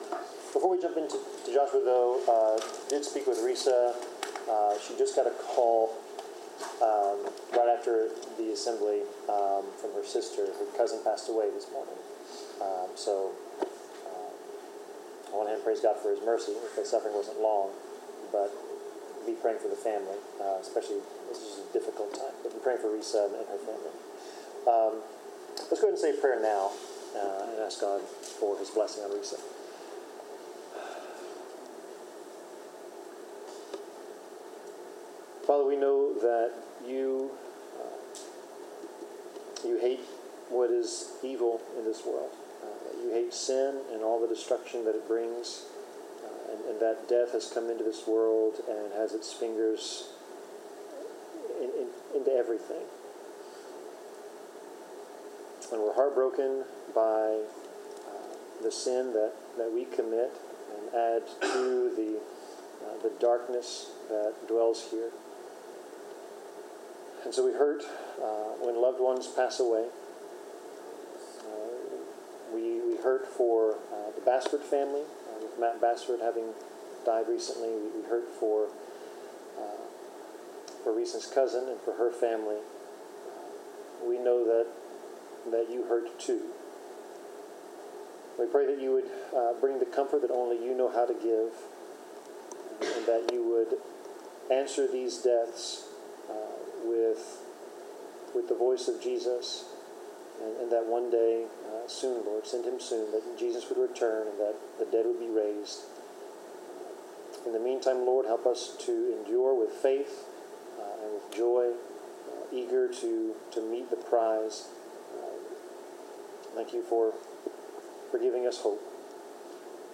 Bible class: Joshua 11
Service Type: Bible Class Topics: Consequences of Sin , Faith , Humanity , Obedience , Promises of God , Prophecy , Trusting in God , Wrath of God